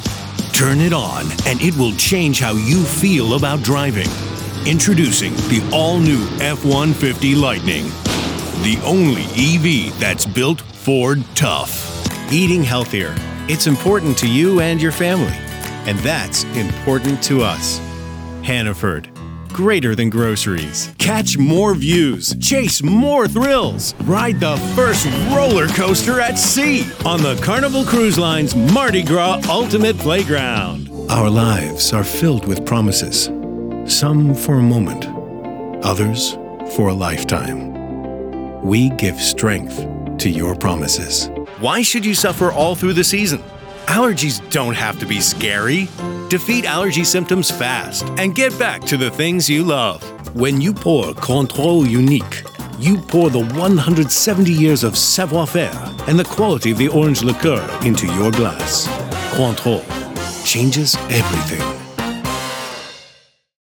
Professional voice over studio with GIK acoustic treatment.
Sprechprobe: Werbung (Muttersprache):